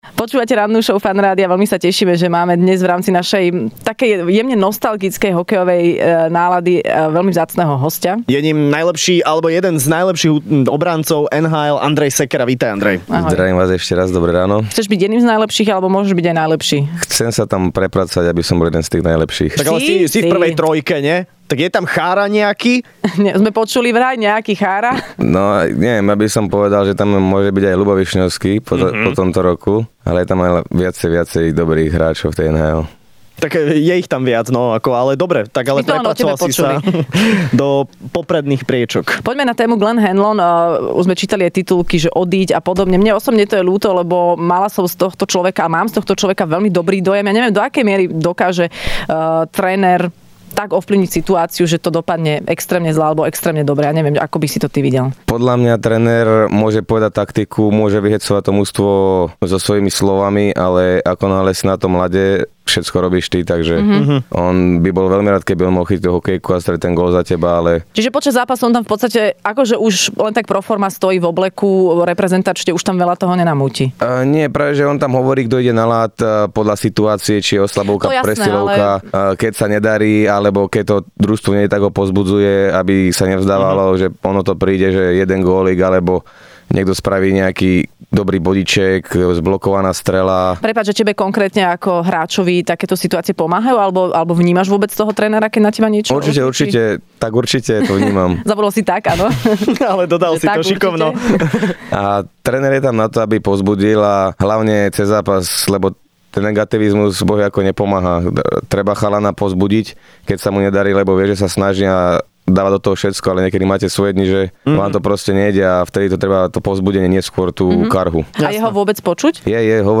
Hosťom v Rannej šou bol hokejový obranca, jeden z najlepších v NHL, Andrej Sekera